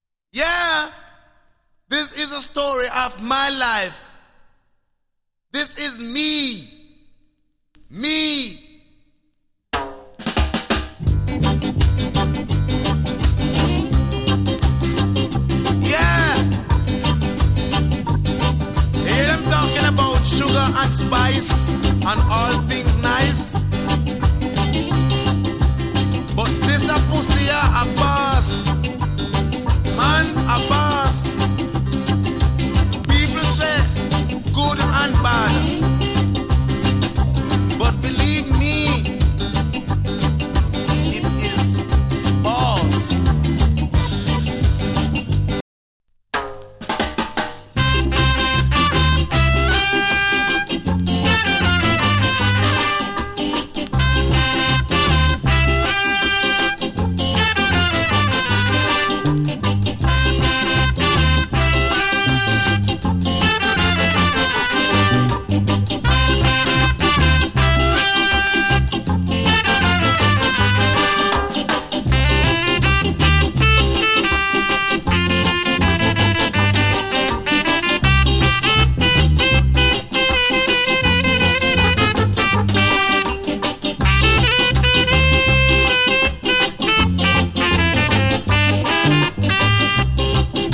GREAT SKINHEAD REGGAE !!